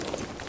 tac_gear_5.ogg